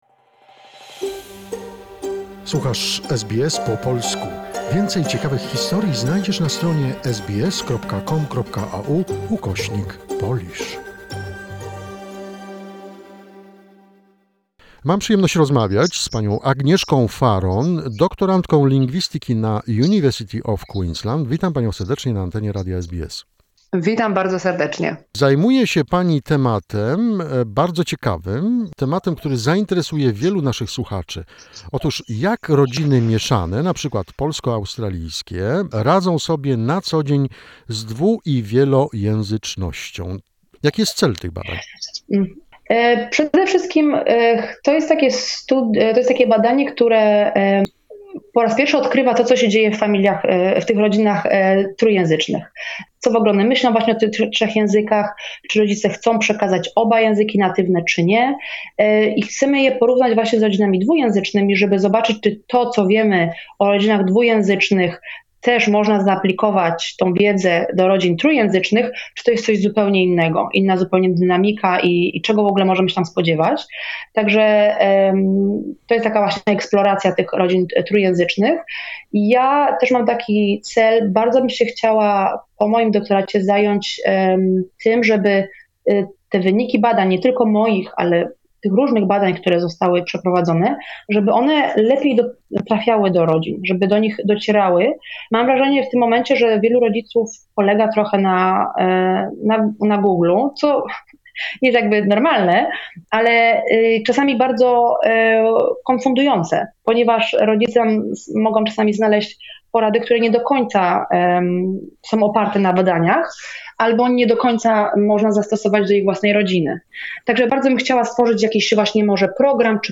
Second part of interview